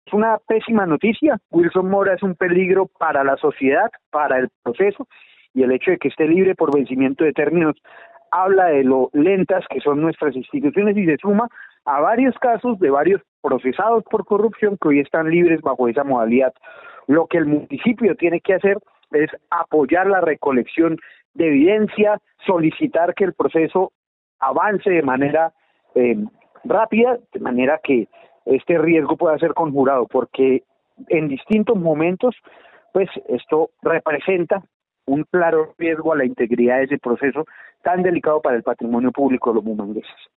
Carlos Parra, concejal de Bucaramanga